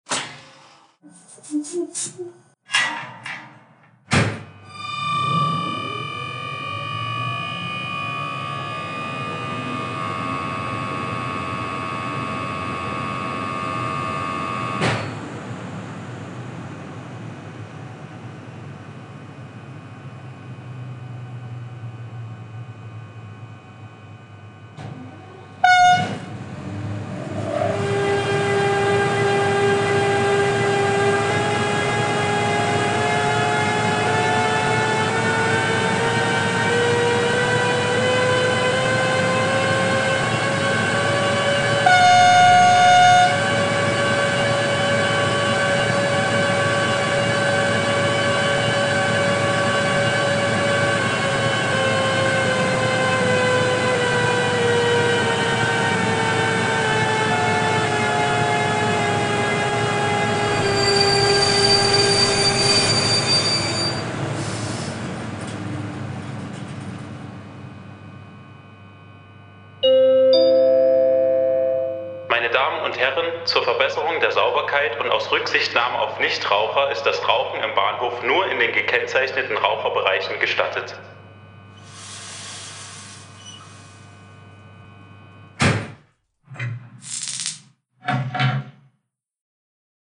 • Ten model w skali H0 jest wyposażony w dekoder dźwiękowy DCC i fabrycznie zamontowany głośnik, oferując realistyczne efekty dźwiękowe, odwzorowujące pracę rzeczywistego elektrowozu (odsłuch 👉